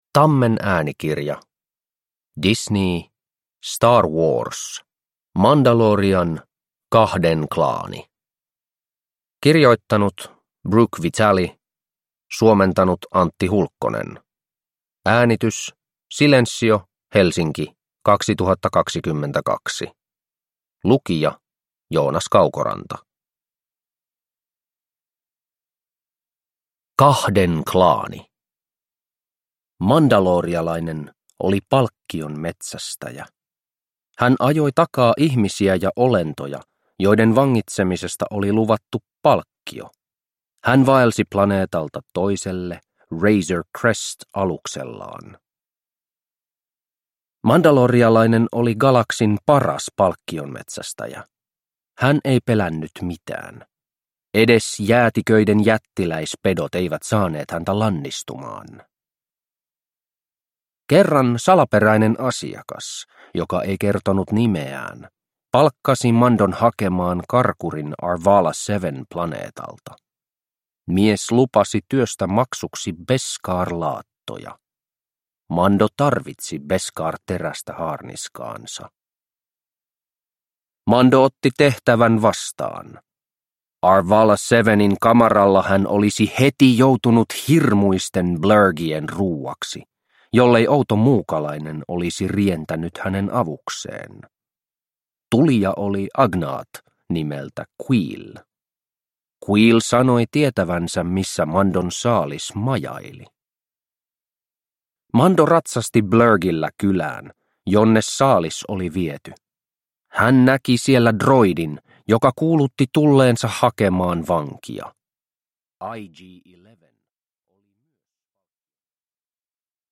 Star Wars. The Mandalorian. Kahden klaani – Ljudbok – Laddas ner